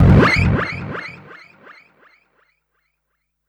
35SFX 02  -R.wav